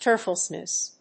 tearfulness.mp3